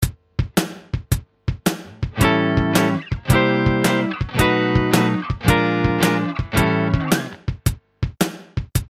One final blues rhythm element is to vary the chords used in the last 2 bars of the 12 bar structure.
Blues Turnaround 3 | Download
blues_turnaround3.mp3